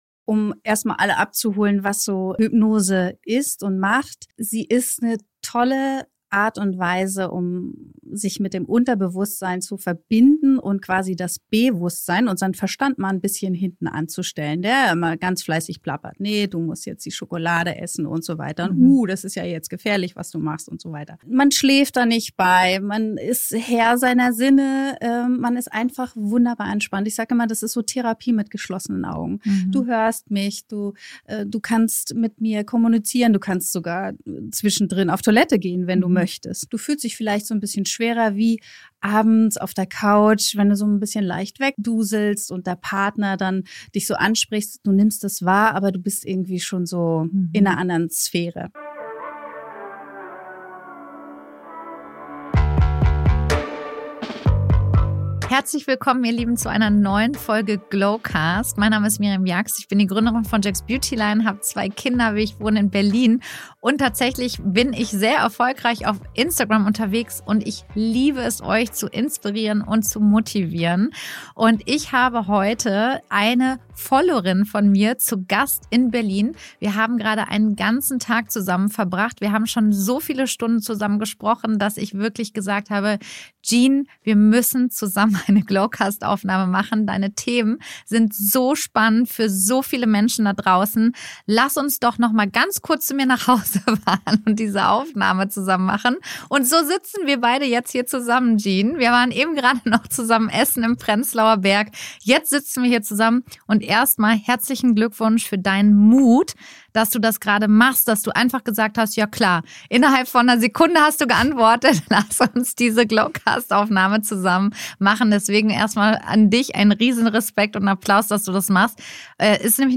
In diesem intensiven Gespräch erfahrt ihr, wie Hypnose wirklich funktioniert (Spoiler: es hat nichts mit dem TV-Show-Klischee zu tun), warum unser Unterbewusstsein der Schlüssel zu nachhaltiger Veränderung ist und wie ihr mit der Kombination aus Hypnose, ätherischen Ölen und Ernährungsberatung eure Ziele endlich erreichen könnt.